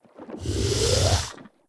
c_seasnake_slct.wav